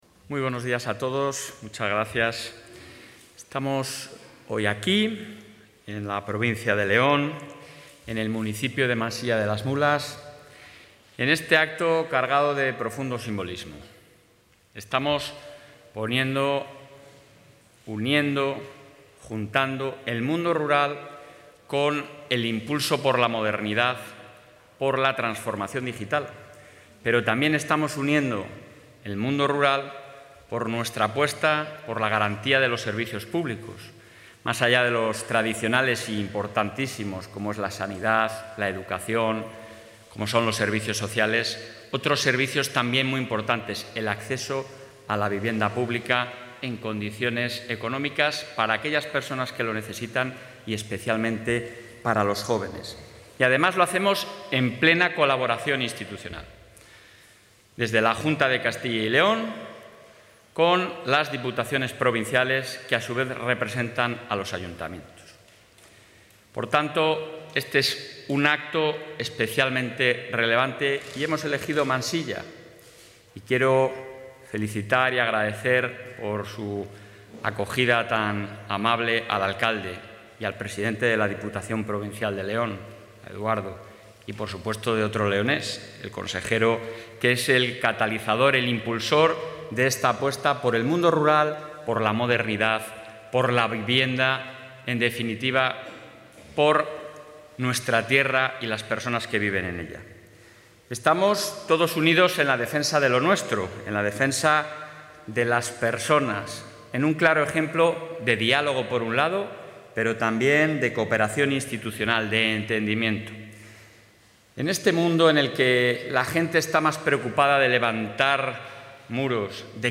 Intervención del presidente.
El presidente de la Junta de Castilla y León, Alfonso Fernández Mañueco, y los presidentes de las nueve diputaciones provinciales han suscrito hoy, en la localidad leonesa de Mansilla de las Mulas, dos importantes protocolos de colaboración que inciden en dos líneas prioritarias en el mundo rural: Internet y el acceso a la vivienda.